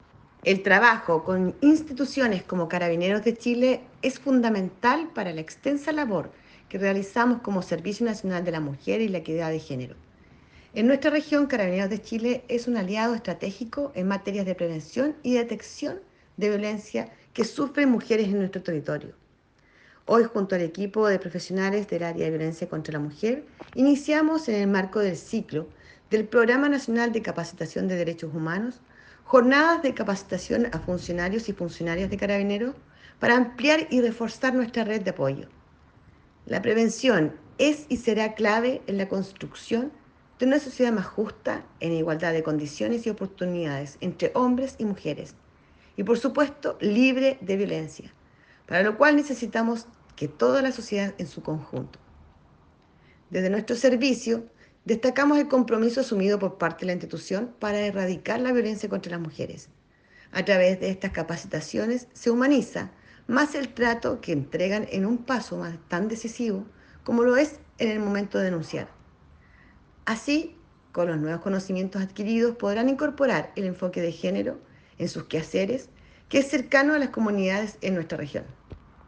CUÑA-DIRECTORA-REGIONAL-DE-SERNAMEG-CAPACITACIÓN-CARABINEROS-MAYO-2023.mp3